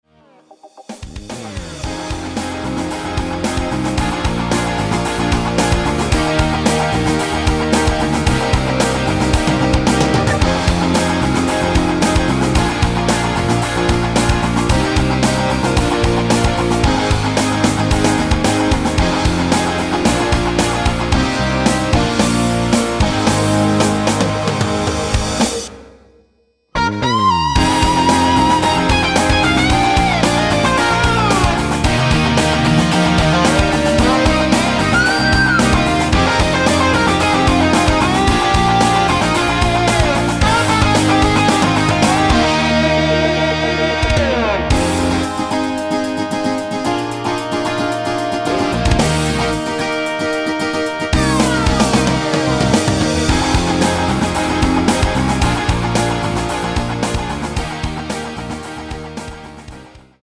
MR입니다.
랩부분 없이 바로 간주로 넘어 갑니다.(미리듣기 참조)
Ab
앞부분30초, 뒷부분30초씩 편집해서 올려 드리고 있습니다.
중간에 음이 끈어지고 다시 나오는 이유는